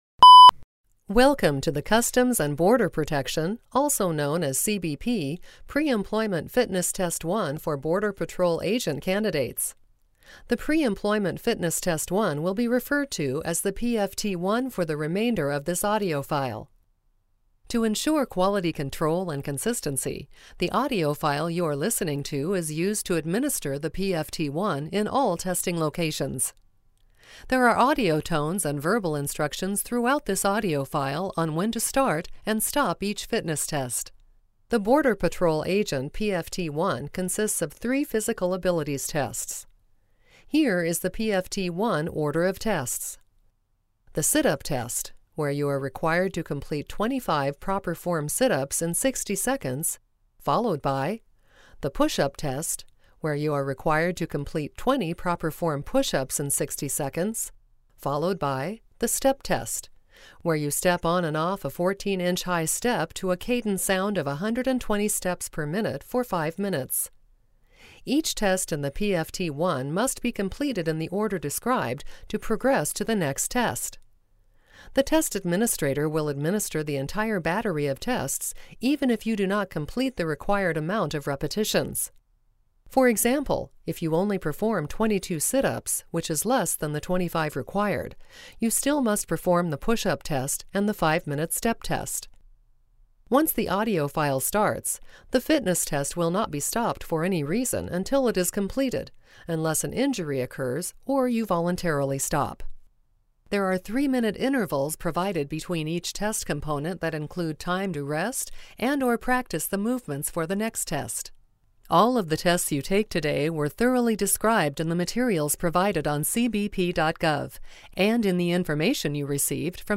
Border Patrol Narrated Preemployment Fitness Test for Test Administrator